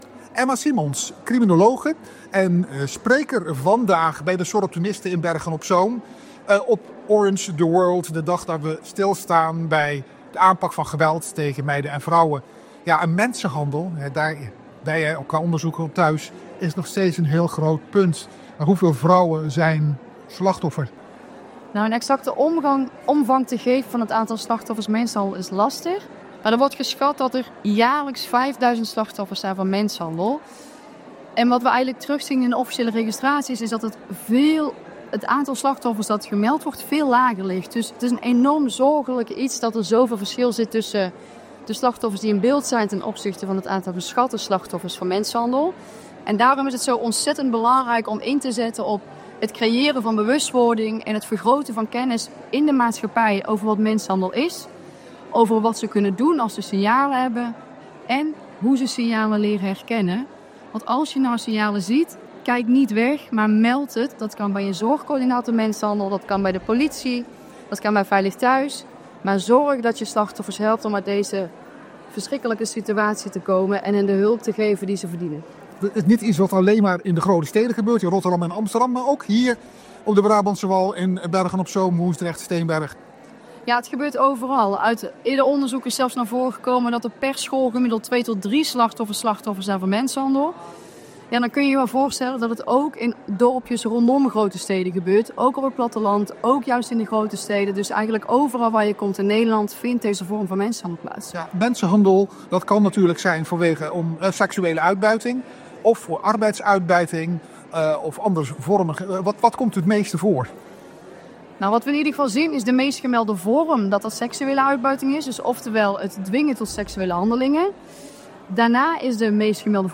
een reportage van de Orange the World-bijeenkomst in het Groot Arsenaal.